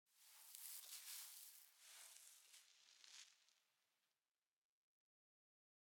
sounds / block / sand / sand13.ogg
sand13.ogg